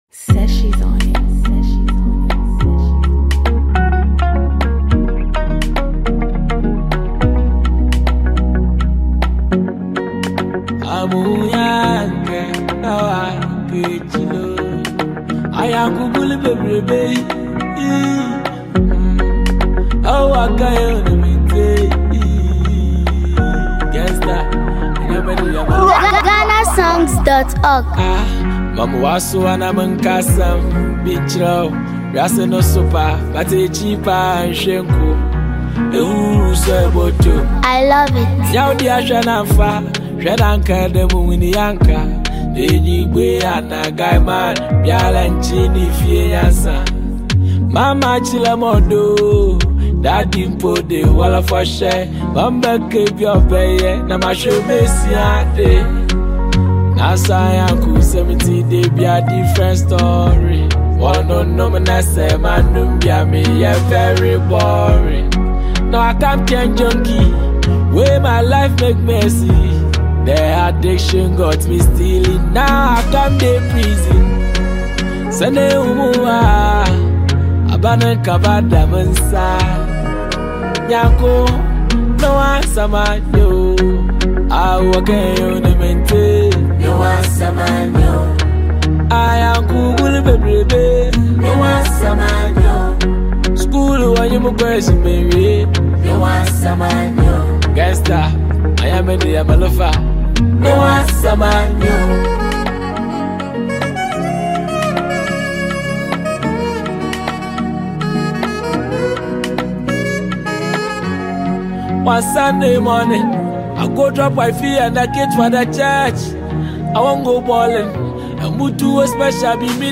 highlife and Afrobeat rhythms
a socially conscious banger